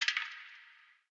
Divergent/ambienturban_13.ogg at 5caee9fba58afd87b69c00b7b52c99a90ffc4eb4
ambienturban_13.ogg